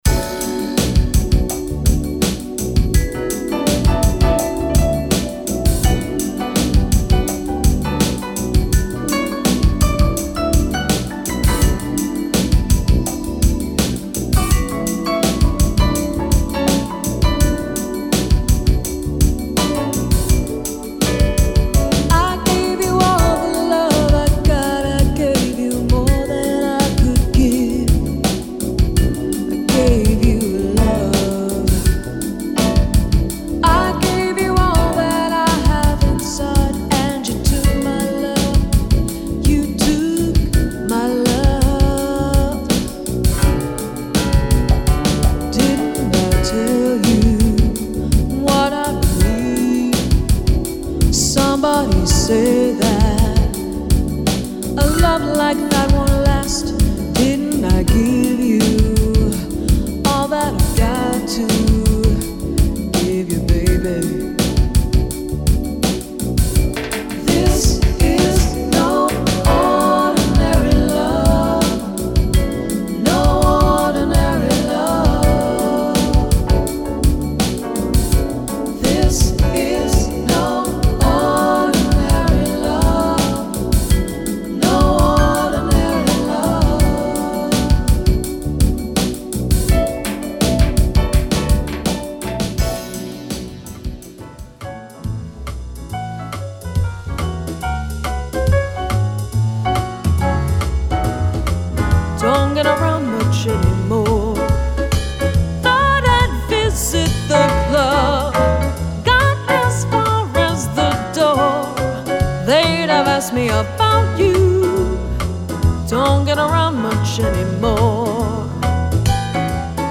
Sample Medley